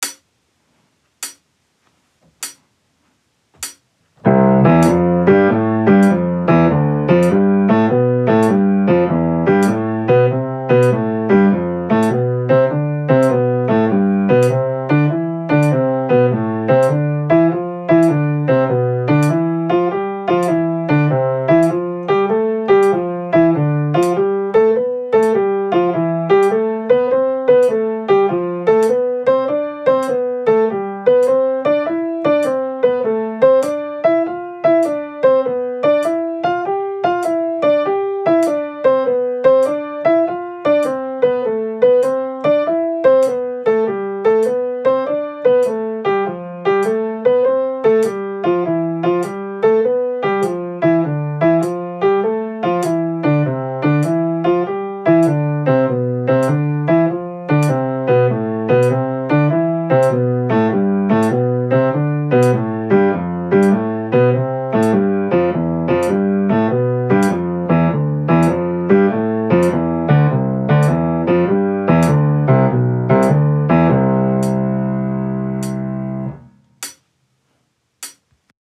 ハノンを使って、ジャズの代表的なリズムであるスウィングのフィーリングをつかみましょう。
例えば♩ =100だったら メトロノームを50に設定します。